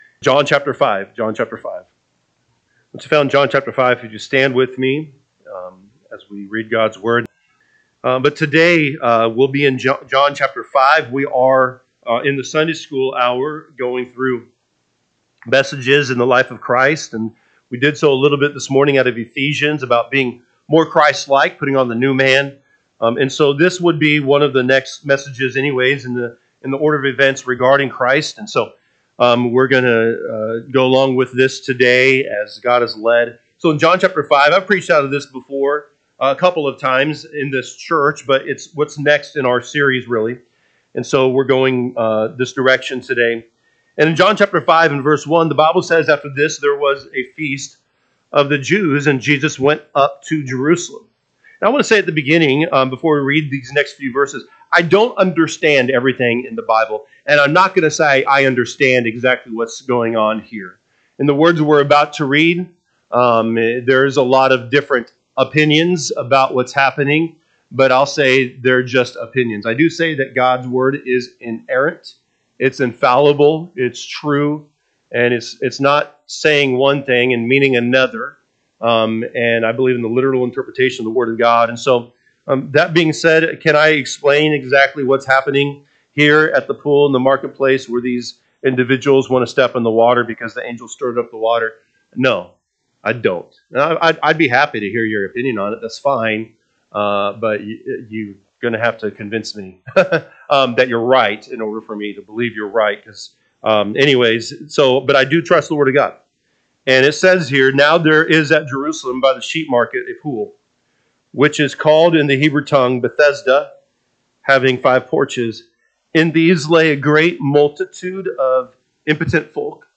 February 9, 2025 am Service John 5:1-14 (KJB) 5 After this there was a feast of the Jews; and Jesus went up to Jerusalem. 2 Now there is at Jerusalem by the sheep market a pool, which is …